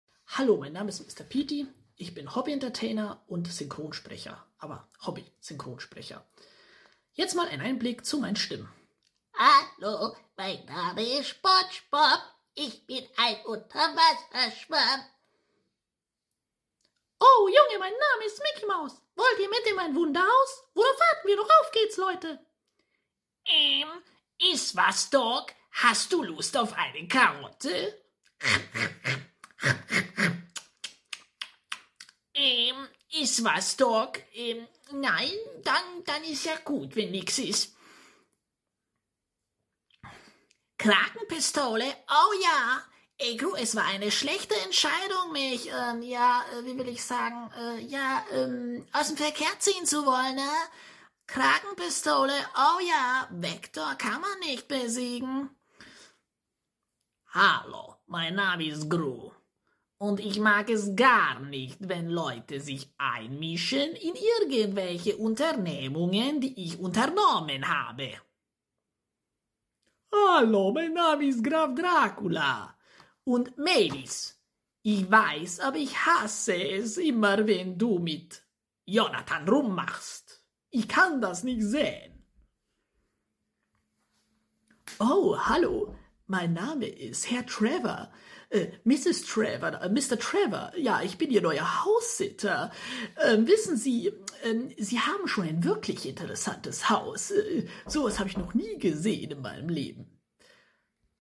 HOBBY SYNCHRONSPRECHER STIMMENIMITATOR MR. sound effects free download